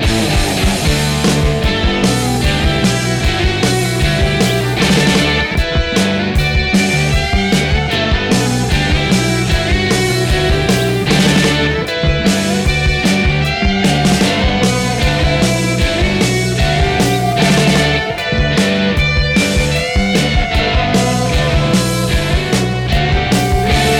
no Backing Vocals Punk 2:38 Buy £1.50